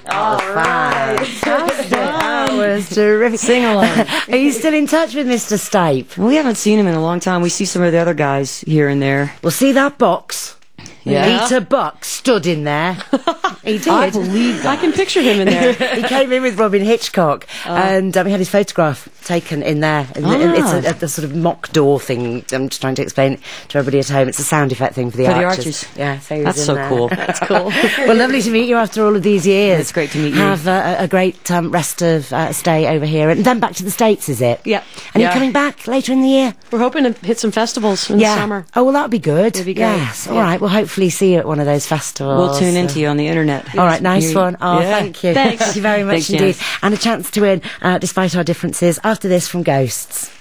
07. interview (0:50)